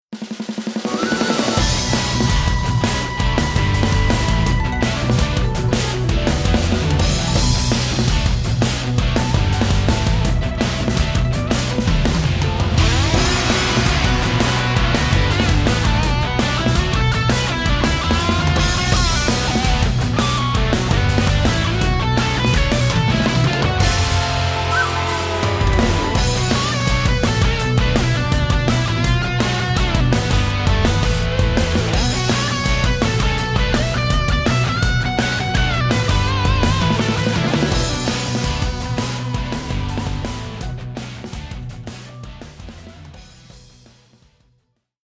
追加戰鬥前或音樂試聽時可選擇之ＢＧＭ。